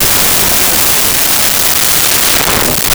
High Powered Rifle
High Powered Rifle.wav